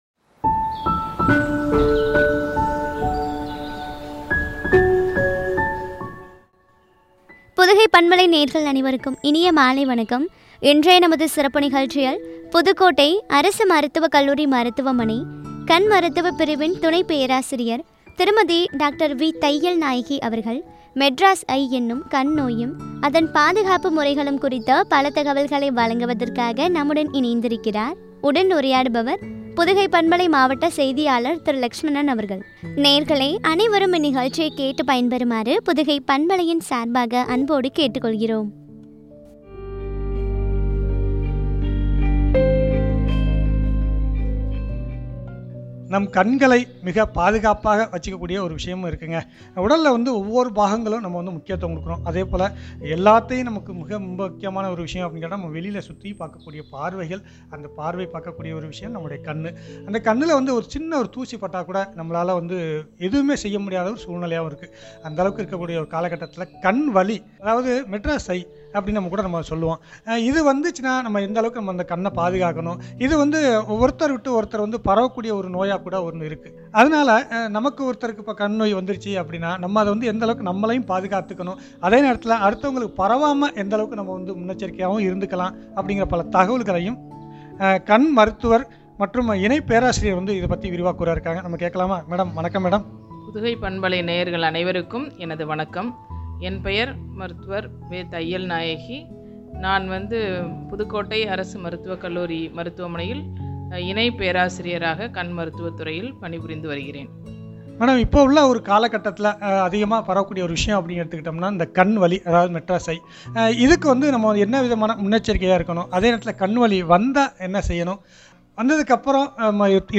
“மெட்ராஸ் ஐ” எனும் கண் நோயும், பாதுகாப்பு முறைகளும் ,பற்றிய உரையாடல்.